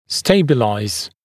[‘steɪbəlaɪz] [‘стэйбэлайз] стабилизировать, делать устойчивым (British English: to stabilise)